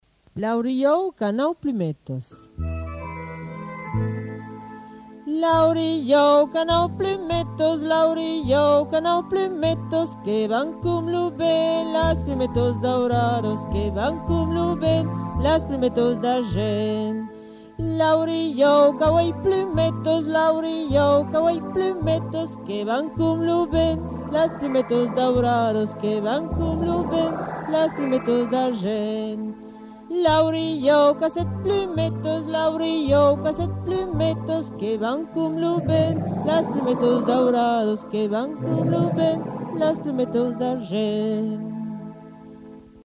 Des Comptines en Béarnais